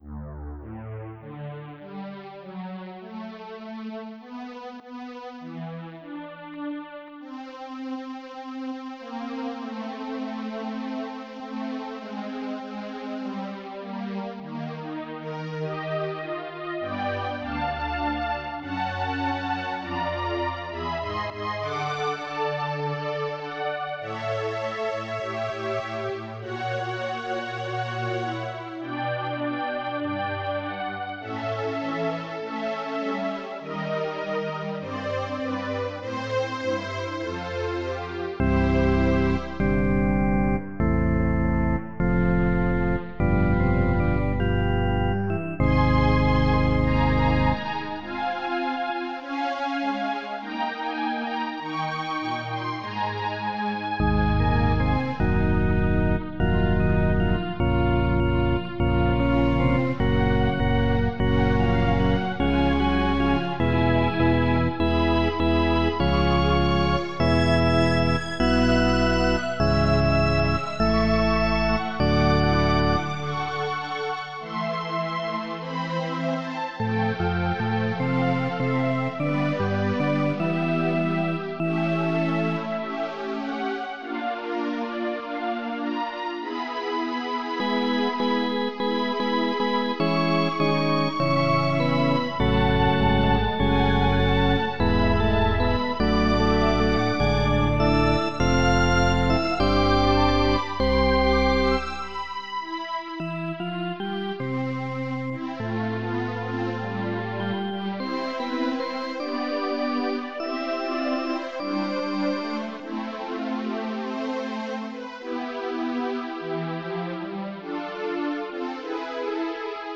This is a "padre nostro" (Our Father) in a very vintage midi version with the score (dated 12 February 2005).